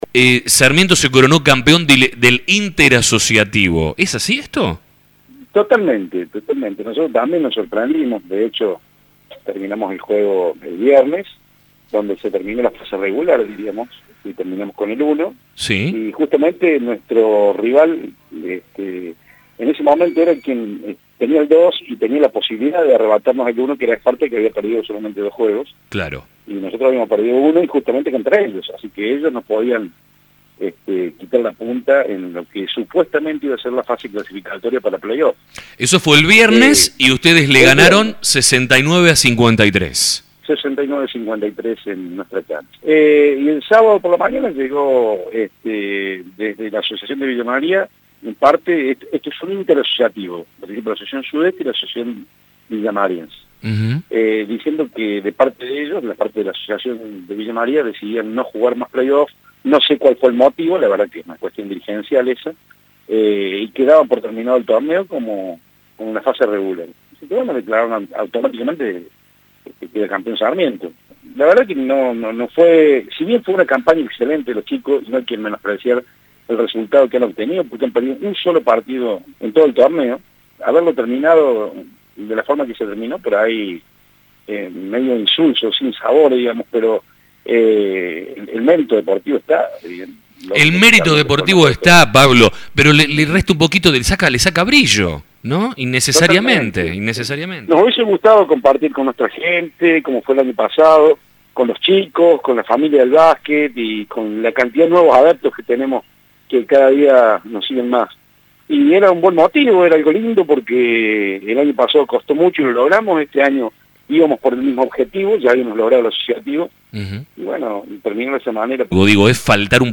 en diálogo con La Mañana de La Urbana